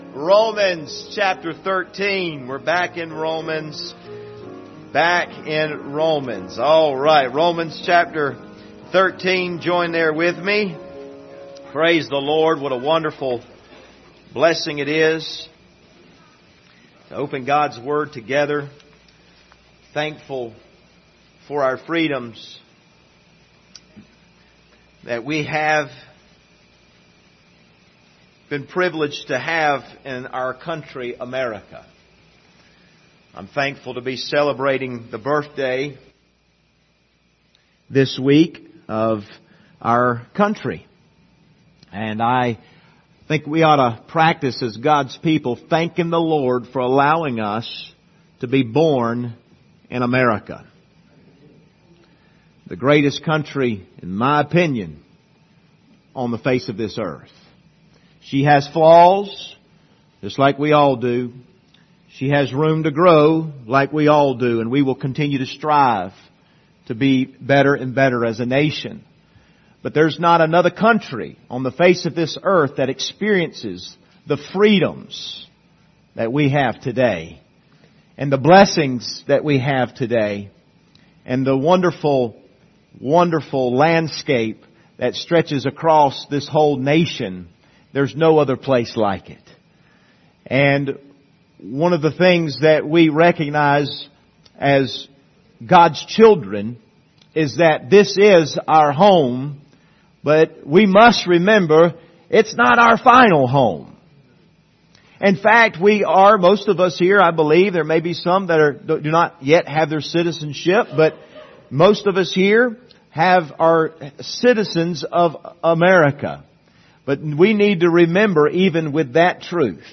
Passage: Romans 13:1-7 Service Type: Sunday Morning